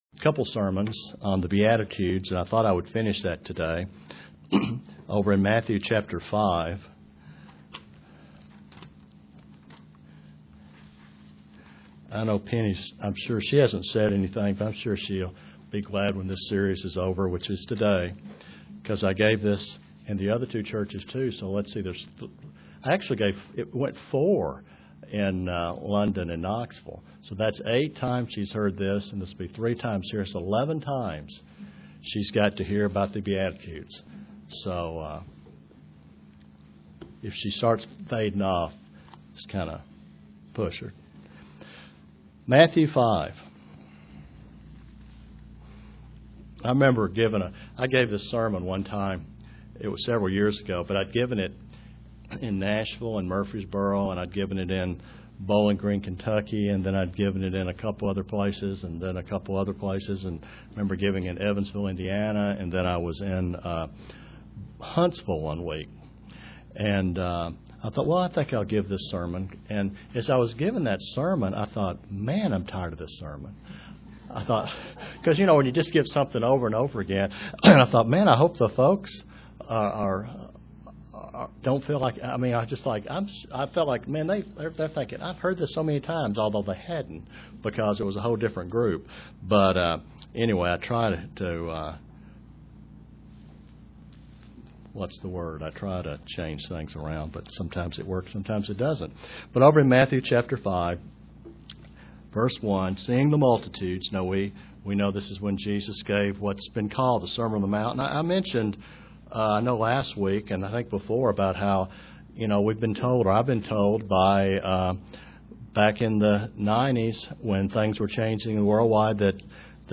Print Concluding study of the beatitudes UCG Sermon Studying the bible?